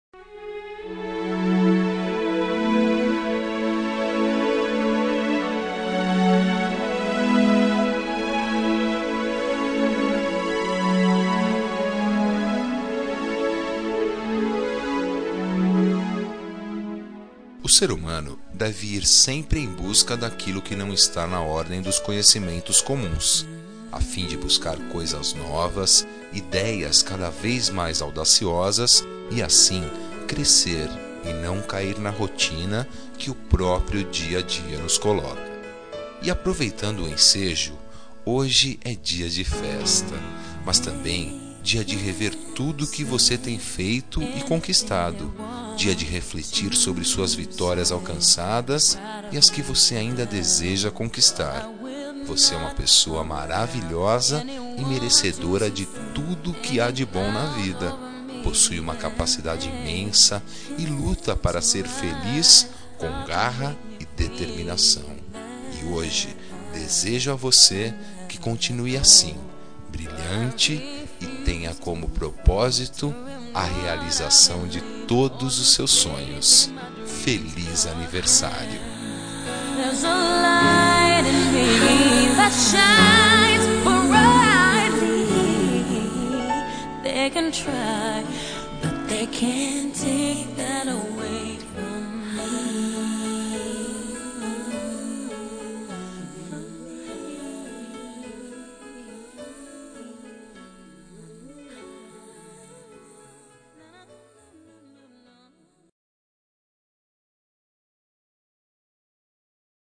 Telemensagem de Aniversário de Pessoa Especial – Voz Masculina – Cód: 1908